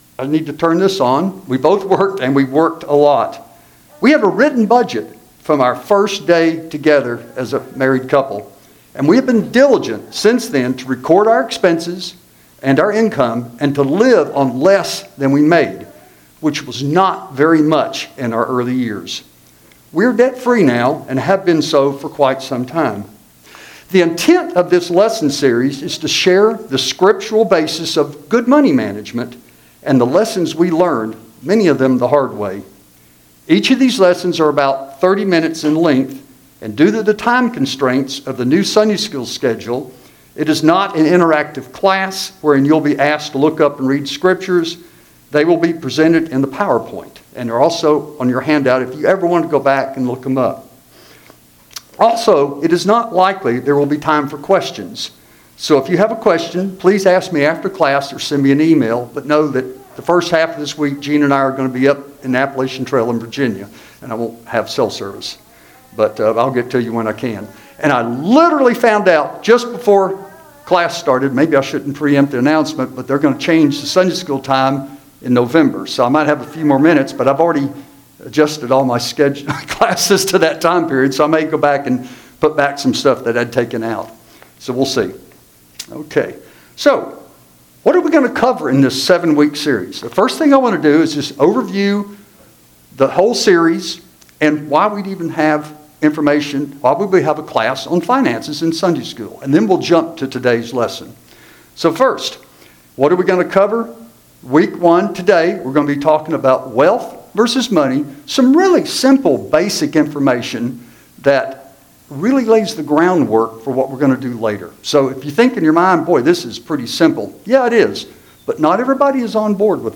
Service Type: Sunday School Handout